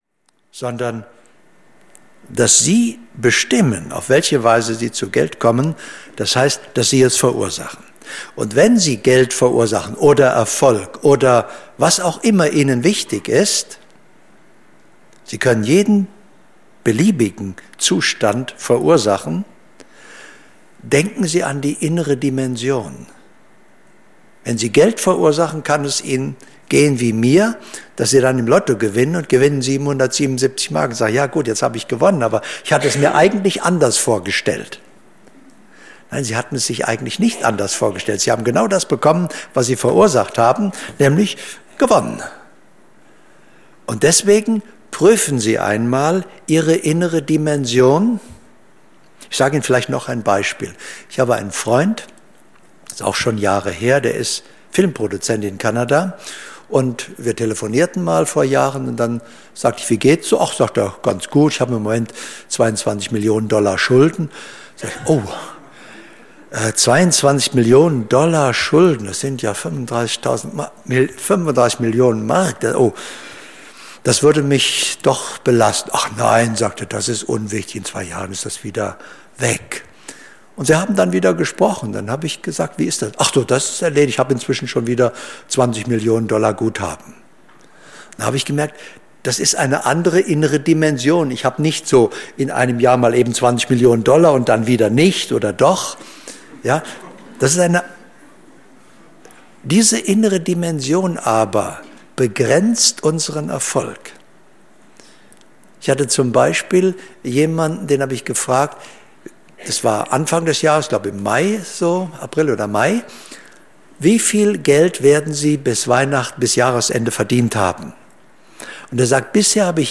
Hörbuch Erfolg und Wohlstand - Golden Classics.